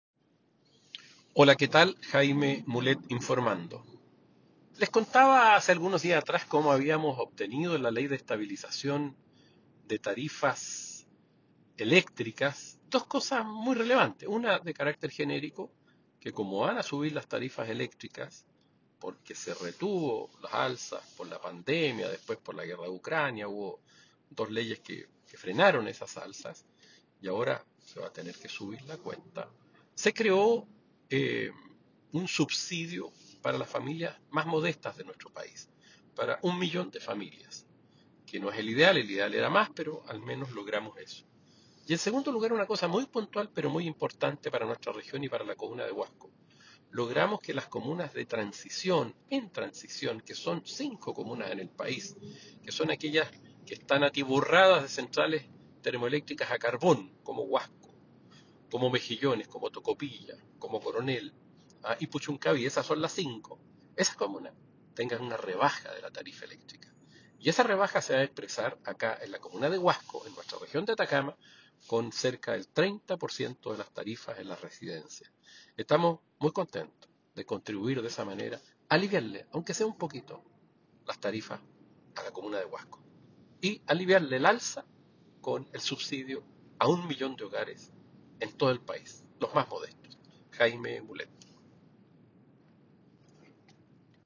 Diputado Jaime Mulet se refiere a las rebajas que obtendrán los hogares de Huasco en las cuentas de la luz.